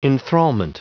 Prononciation du mot enthrallment en anglais (fichier audio)
Prononciation du mot : enthrallment